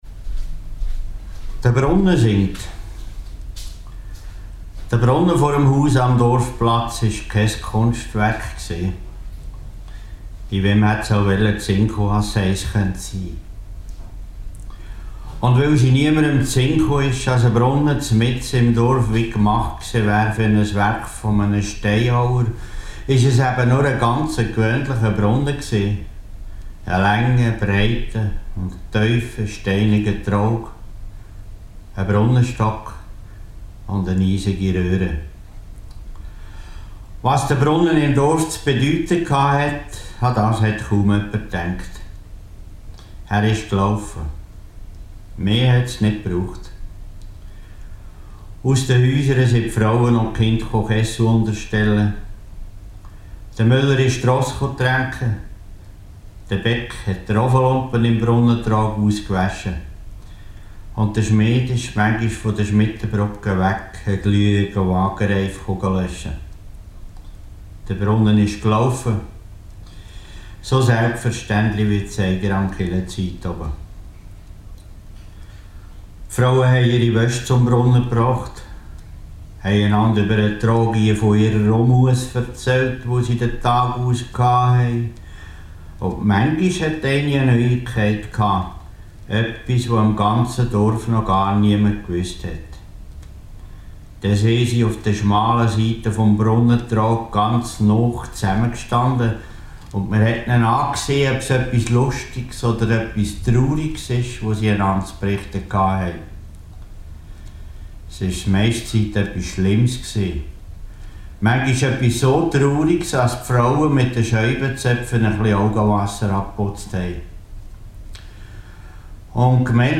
erzählt seine Geschichte «De Brunne singt